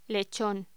Locución: Lechón
voz